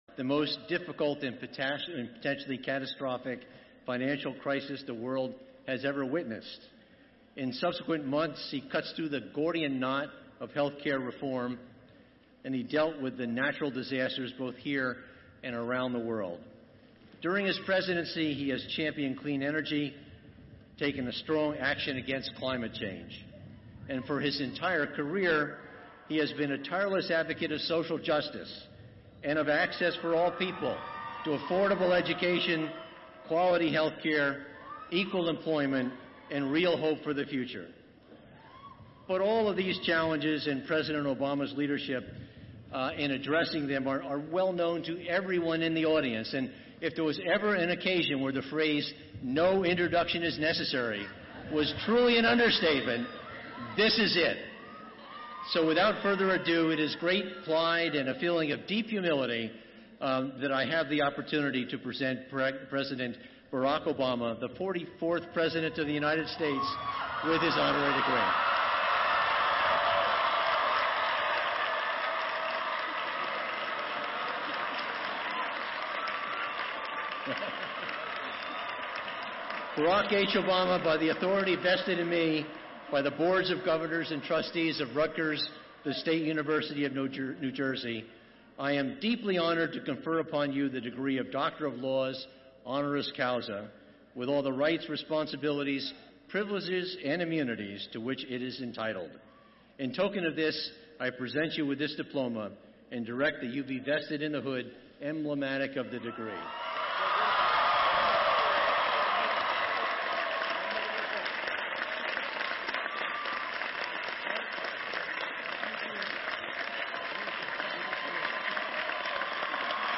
Below is the full transcript of the commencement speech delivered by President Obama at Rutgers 2016 commencement ceremony held on Sunday, May 15, 2016.